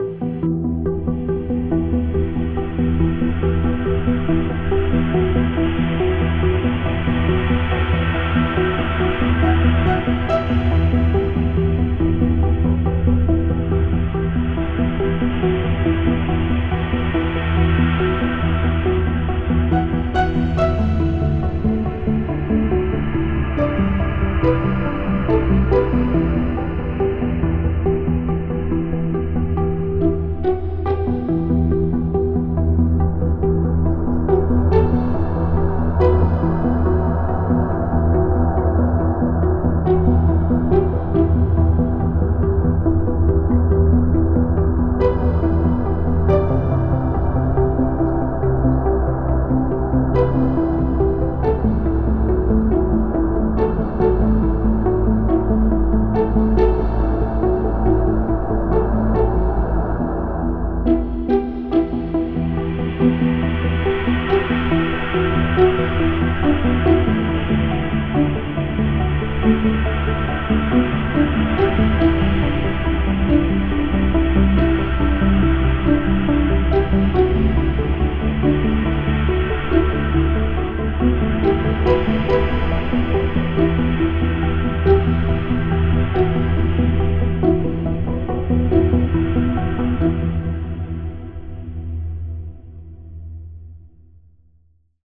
Ambient music pack
Ambient Recollect Intensity 2.wav